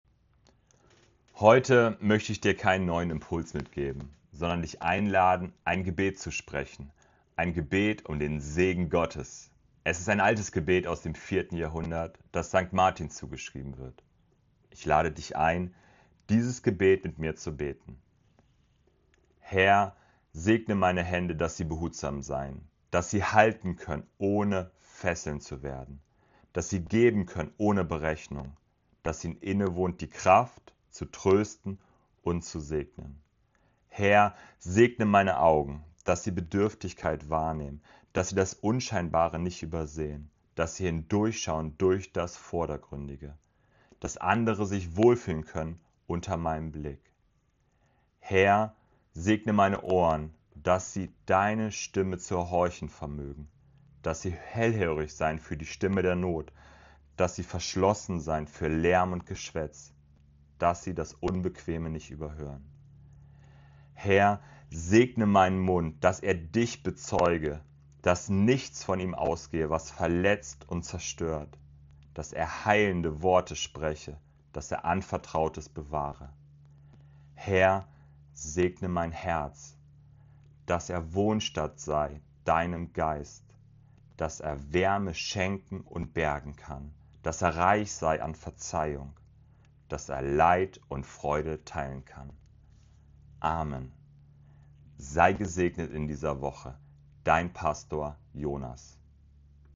Segensgebet